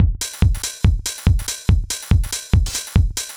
Index of /musicradar/uk-garage-samples/142bpm Lines n Loops/Beats
GA_BeatA142-05.wav